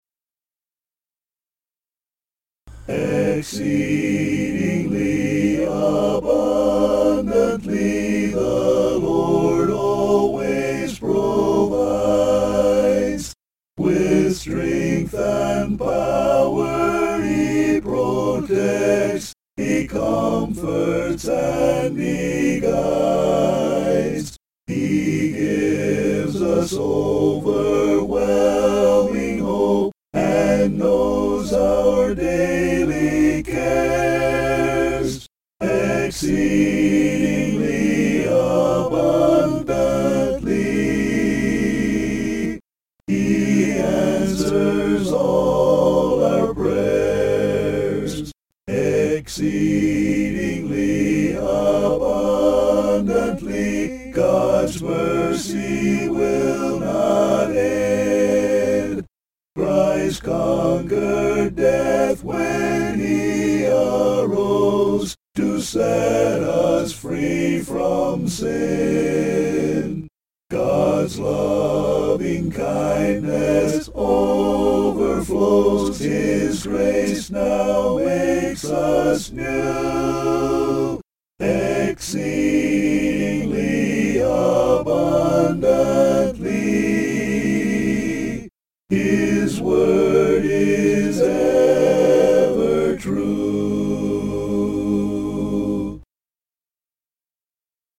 (An original hymn)
vocals